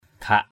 /ɡʱaʔ/